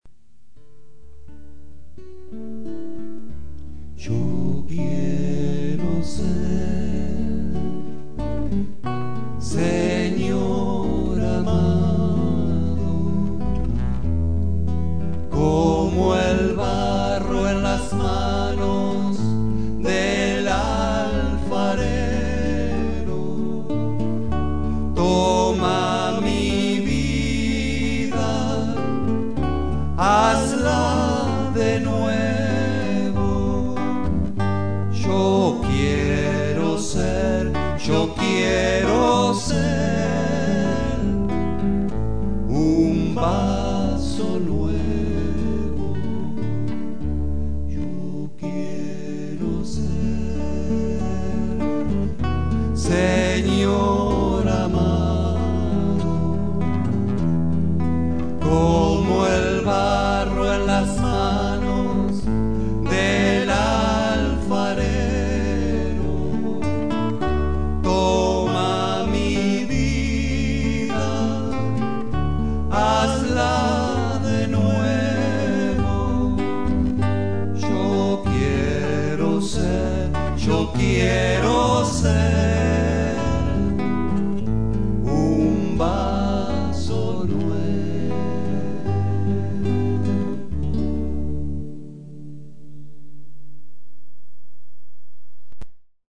voz y guitarra
bajo